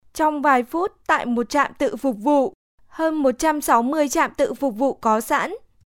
All voiceover projects are recorded and mixed by our in-house audio engineers to ensure high fidelity and natural sounding recordings.
Some of our Voice Over Samples
Vietnamese – Female voice
2023-Vietnamese-Female.mp3